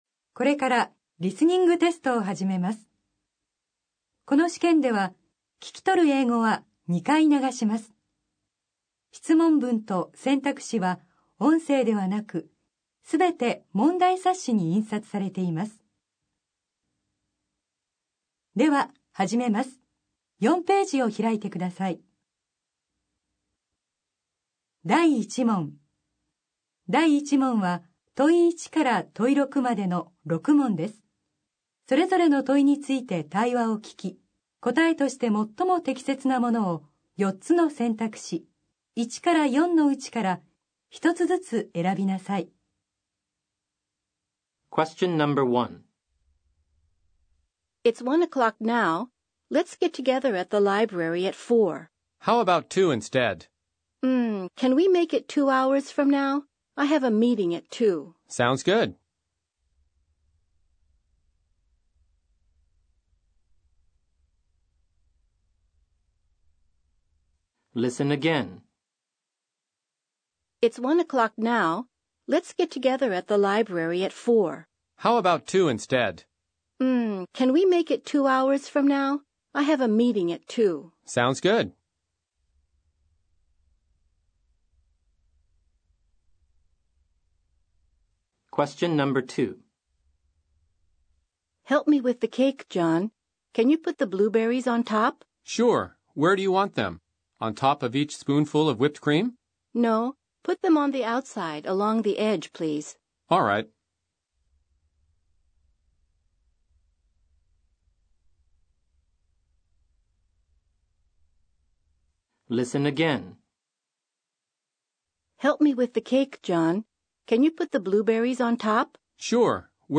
英語リスニング 過去問の全て